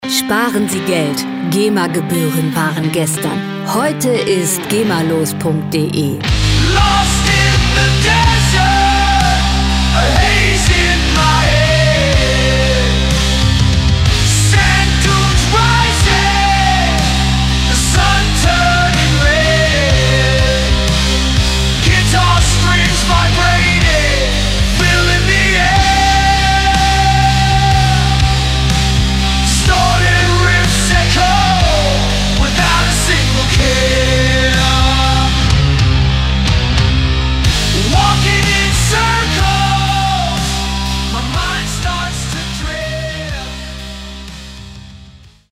Rockmusik - Harte Männer
Musikstil: Hardrock
Tempo: 123 bpm
Tonart: C-Moll
Charakter: massiv, wuchtig
Instrumentierung: E-Gitarren, E-Bass, Drums